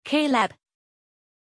Pronunciation of Caleb
pronunciation-caleb-zh.mp3